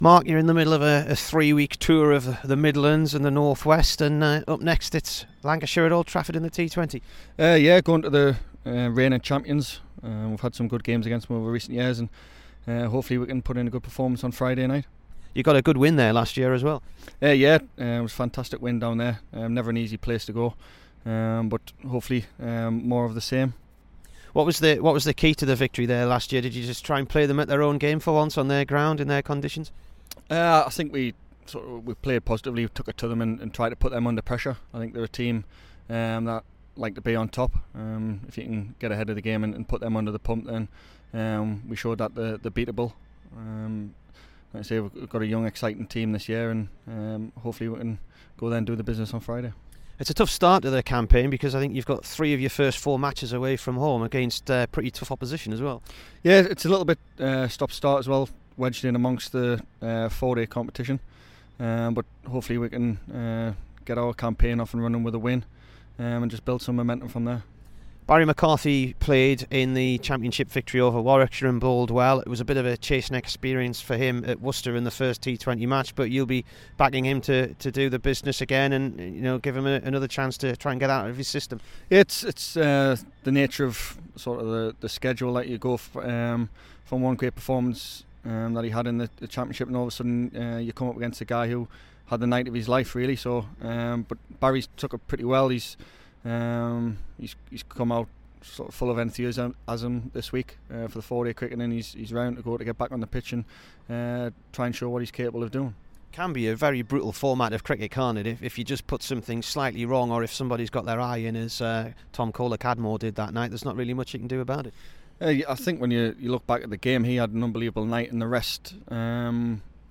MARK STONEMAN INT